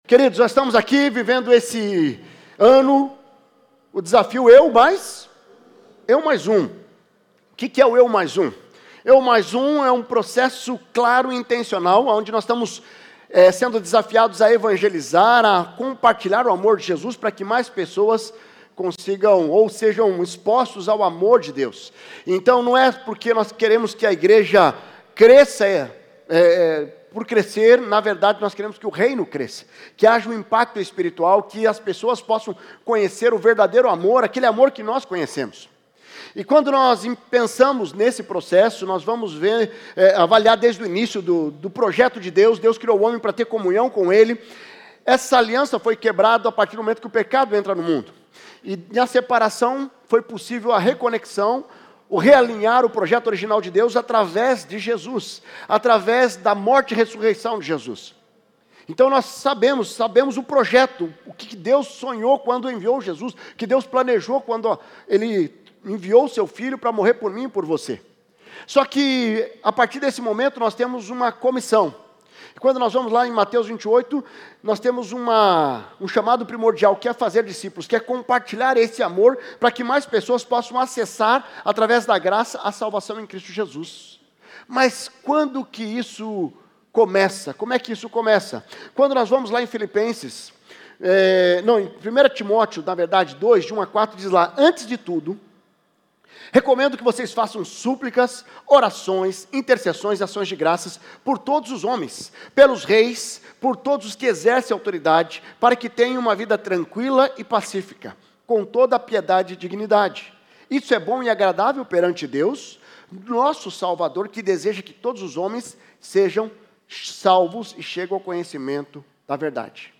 Igreja Batista do Bacacheri